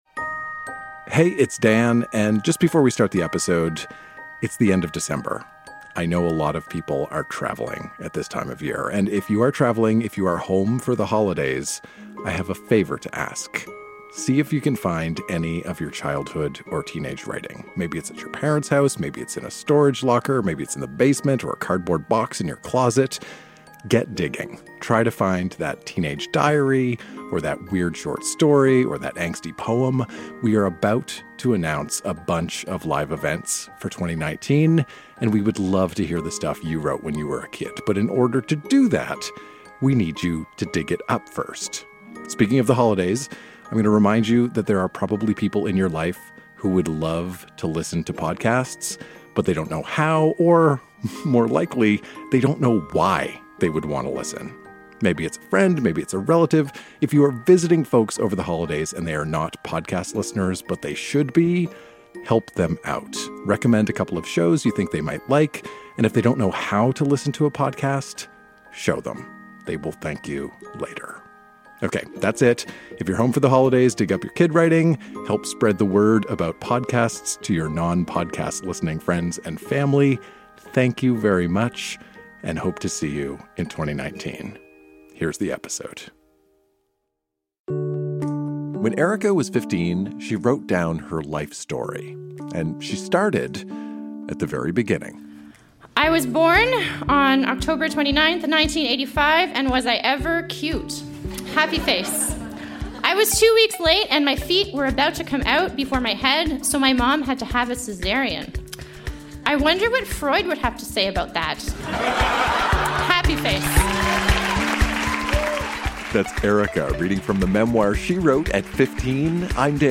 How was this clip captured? Recorded live at La Sala Rossa in Montréal.